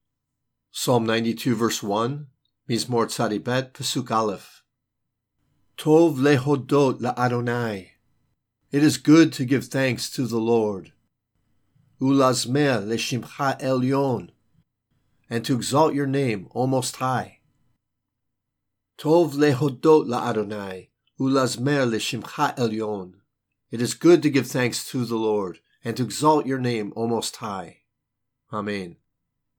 Psalm 92:1 reading (click for audio):